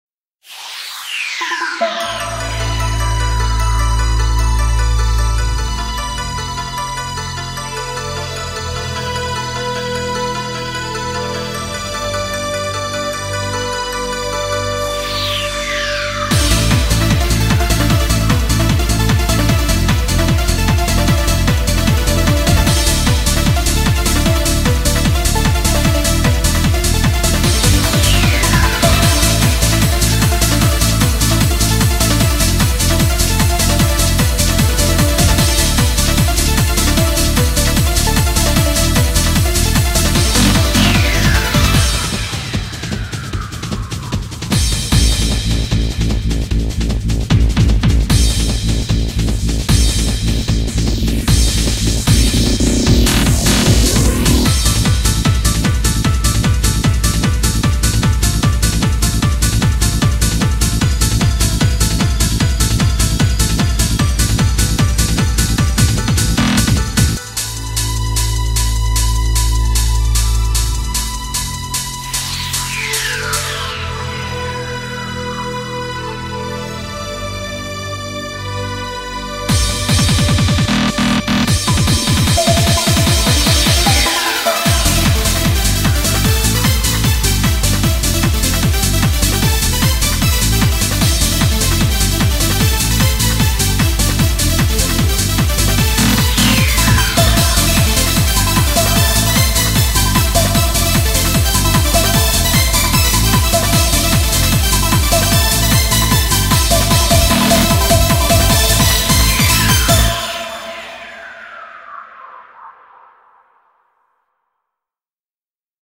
BPM151
Audio QualityPerfect (Low Quality)